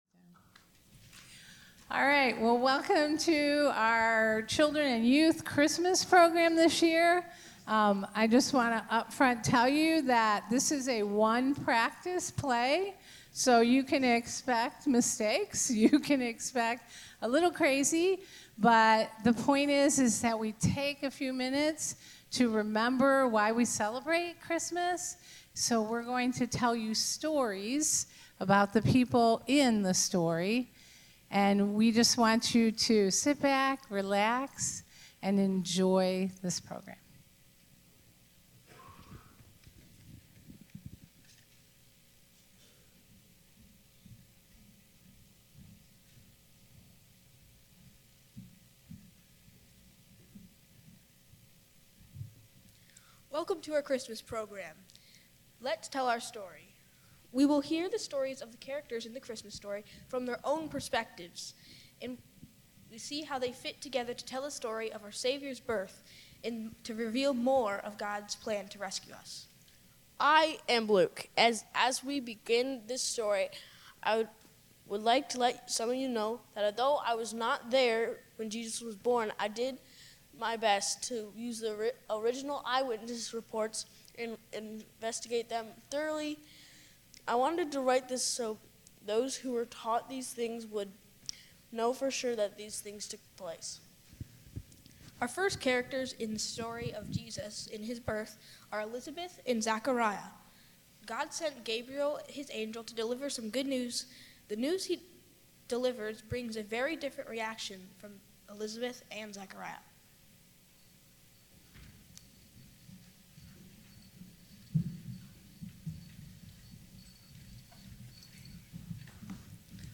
Sermons | First Alliance Church Toledo
December 14, 2025 Advent : Joy Kids Christmas Program